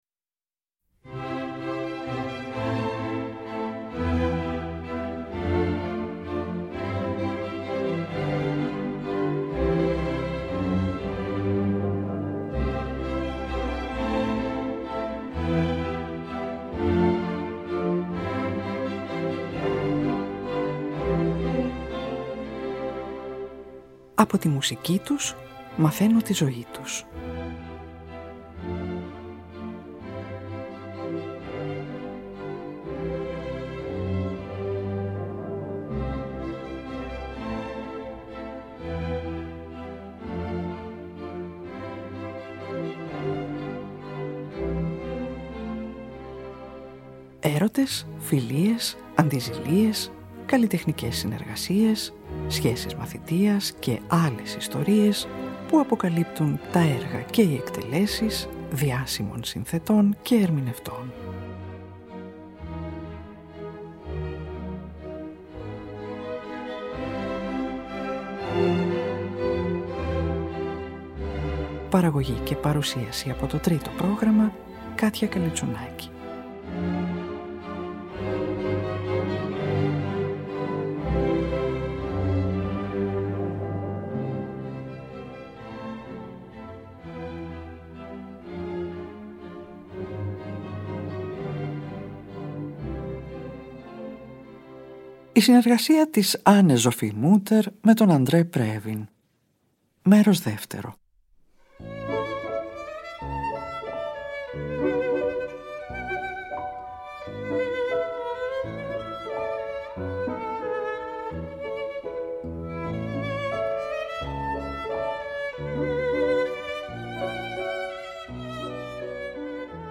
Η Anne-Sophie Mutter με τον André Previn ως μαέστρο παίζει με τη Φιλαρμονική της Βιέννης το 2ο μέρος του Κοντσέρτου για Βιολί του P.I.Tchaikovsky (ζωντανή ηχογράφηση – Βιέννη 2003) και με τη Συμφωνική του Λονδίνου το 1ο μέρος του Κοντσέρτου για Βιολί του Erich Korngold (Λονδίνο 2004).